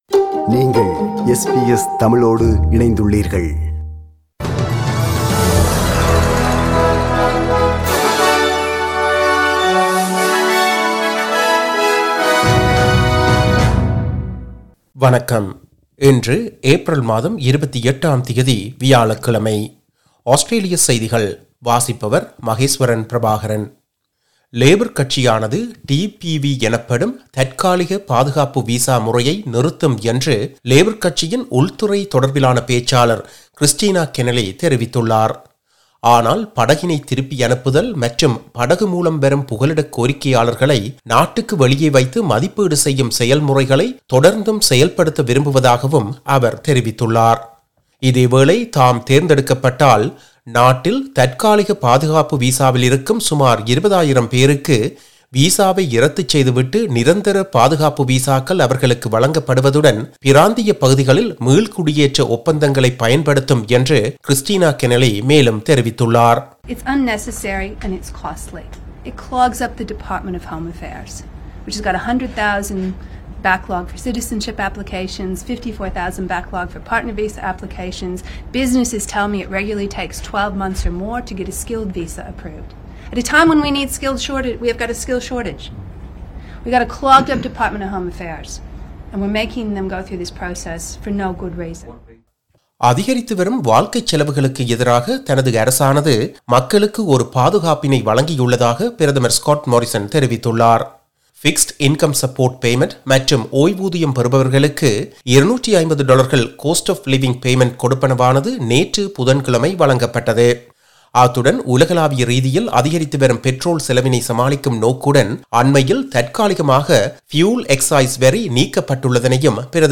Australian news bulletin for Thursday 28 April 2022.